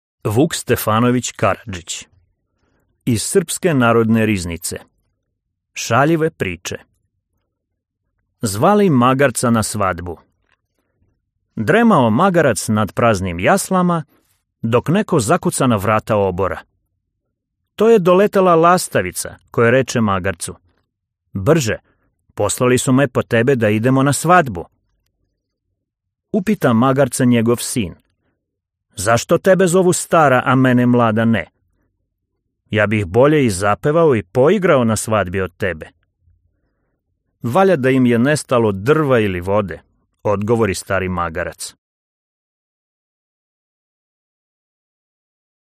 Аудиокнига Сербские рассказы и сказки | Библиотека аудиокниг
Прослушать и бесплатно скачать фрагмент аудиокниги